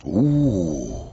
zen_ooooohhhh.wav